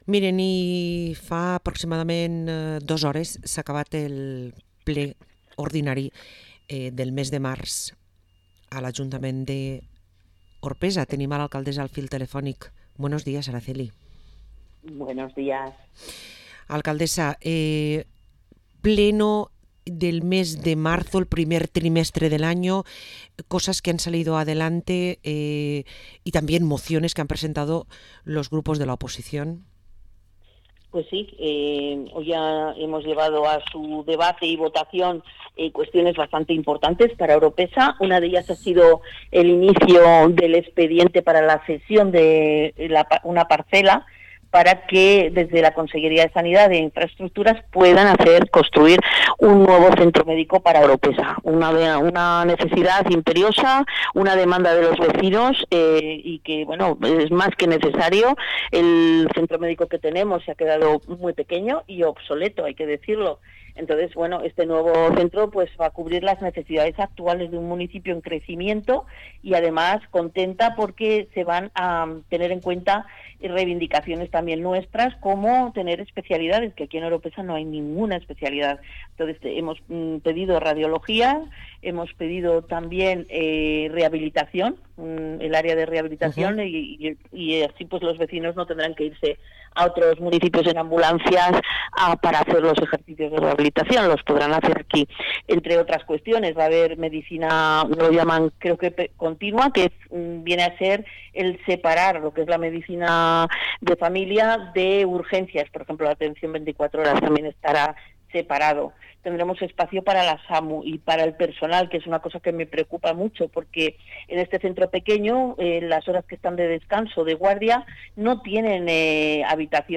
Parlem amb Araceli de Moya, Alcaldessa d´Orpesa